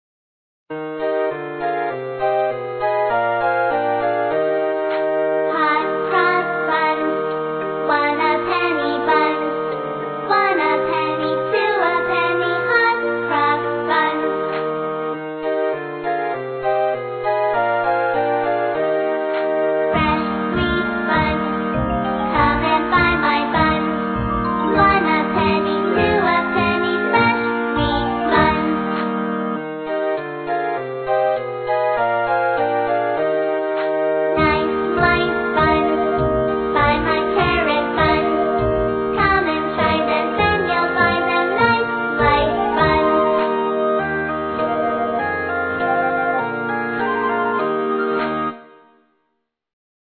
在线英语听力室英语儿歌274首 第72期:Hot Cross Buns的听力文件下载,收录了274首发音地道纯正，音乐节奏活泼动人的英文儿歌，从小培养对英语的爱好，为以后萌娃学习更多的英语知识，打下坚实的基础。